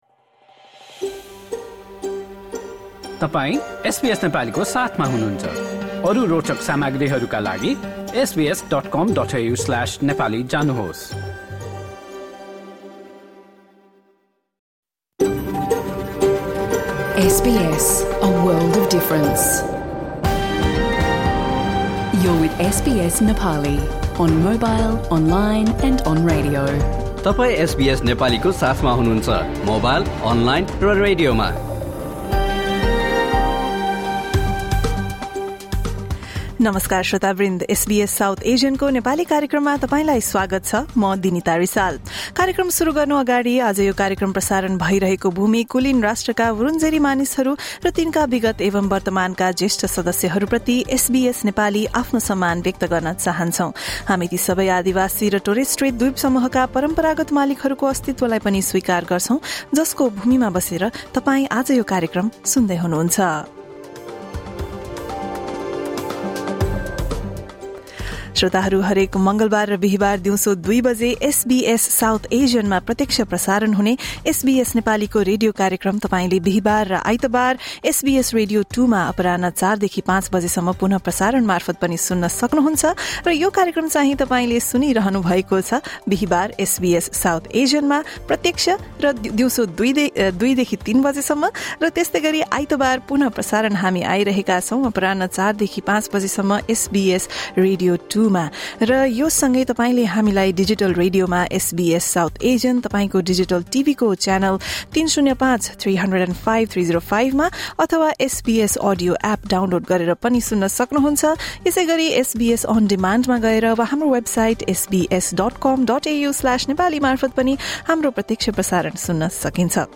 SBS Nepali’s radio broadcast from Thursday, 2 October 2025